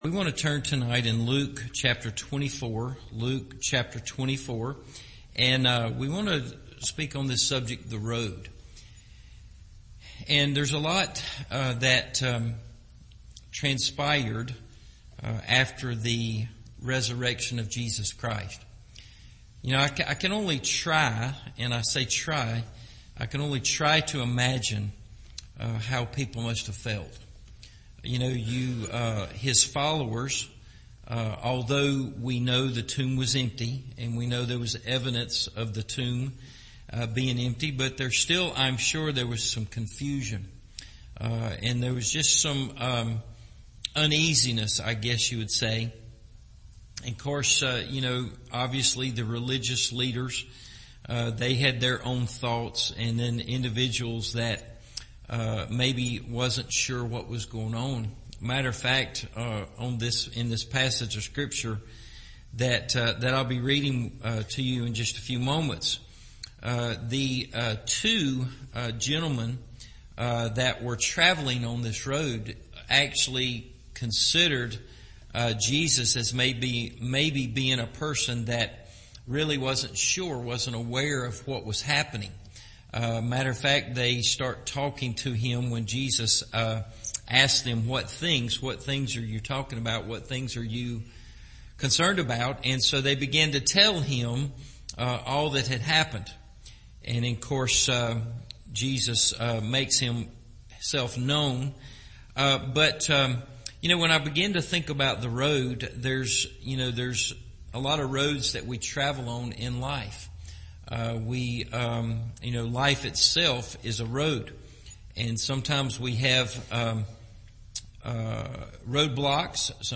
The Road – Evening Service